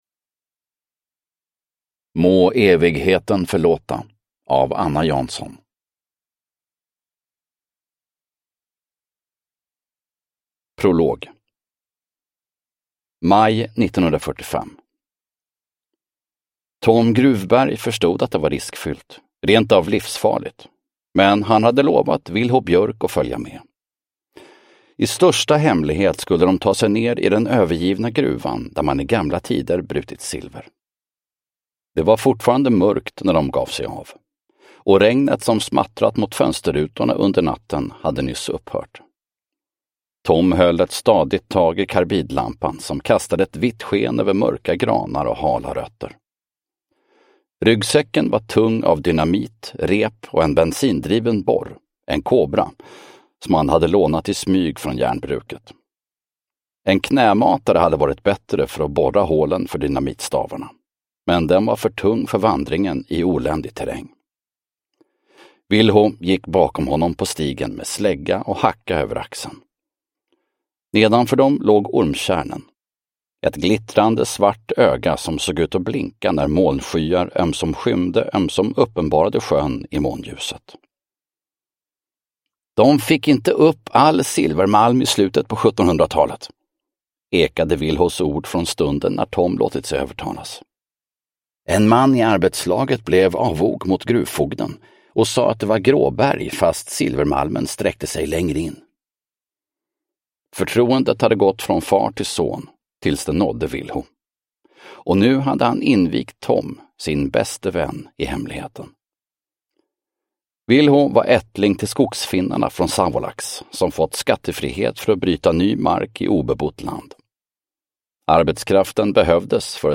Må evigheten förlåta – Ljudbok – Laddas ner